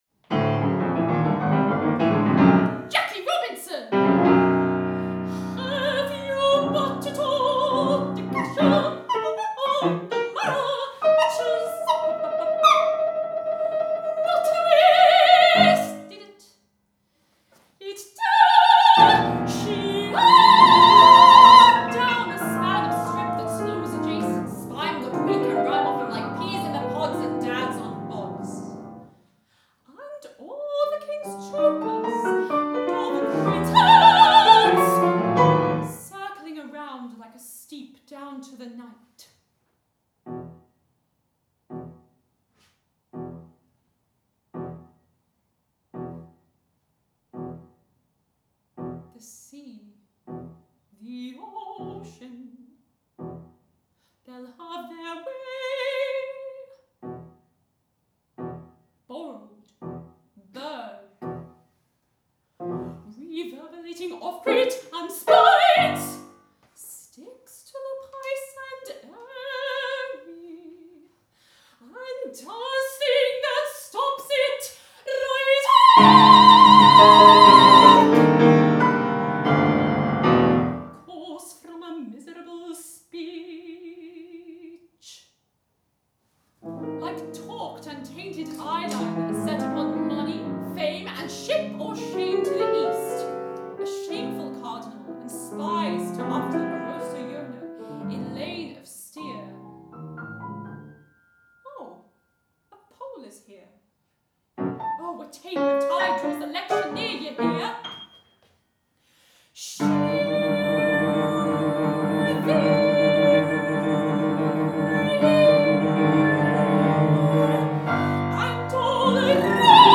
for mezzo-soprano and piano
Recorded in a workshop in Oxford